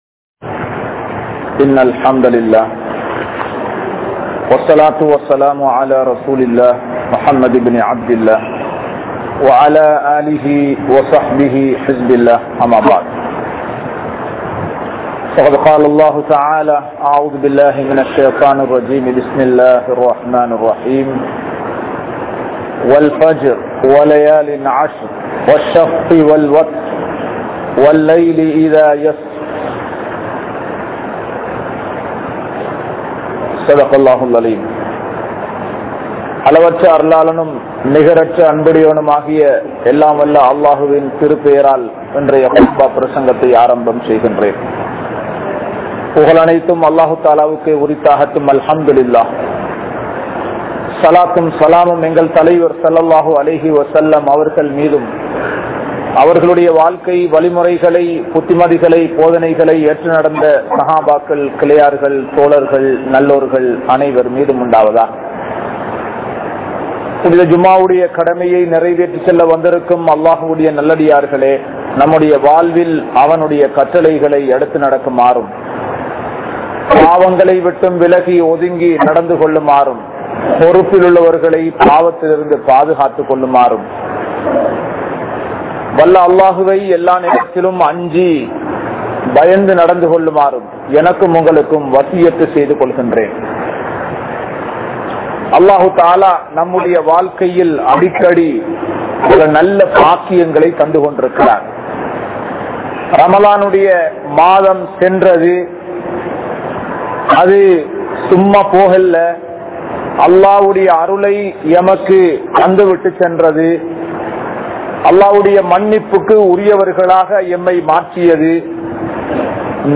Importance Of First 10 Days Of Dhul Hijjah (துல்ஹிஜ்ஜா மாதத்தின் ஆரம்ப 10 நாட்களின் சிறப்புகள்) | Audio Bayans | All Ceylon Muslim Youth Community | Addalaichenai